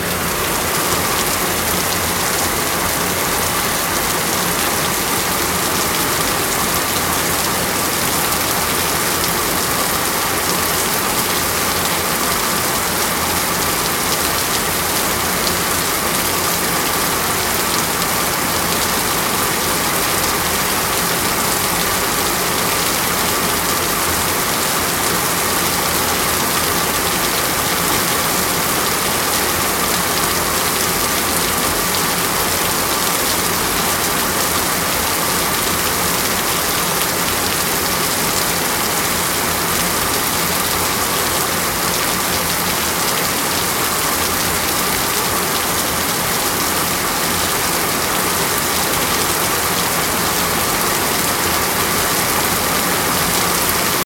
rain-3.ogg